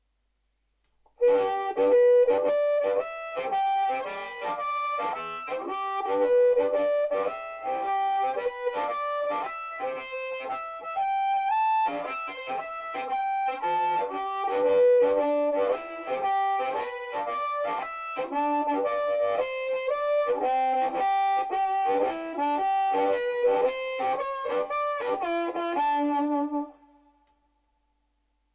Octaving The Blues On Harmonica
Now you just need to run the whole thing together through your bullet mic and valve amp.